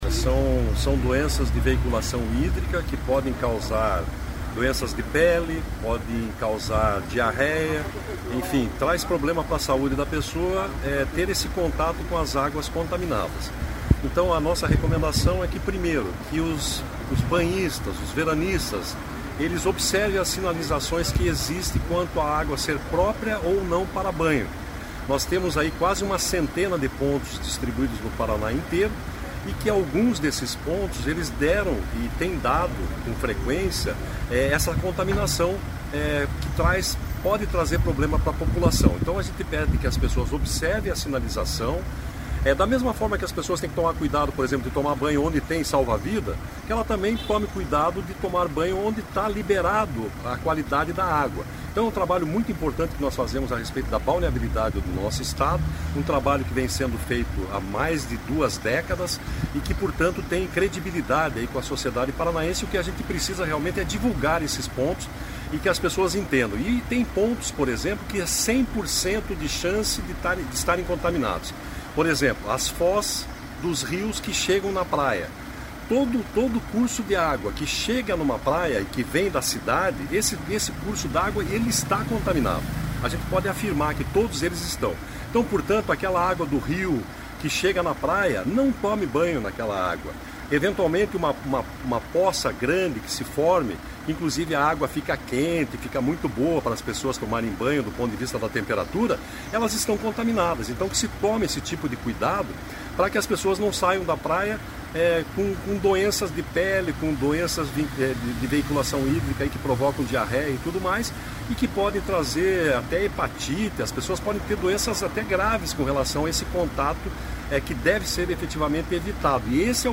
Sonora do diretor-presidente do IAT, Everton Souza, sobre o último boletim de balneabilidade do Verão Maior Paraná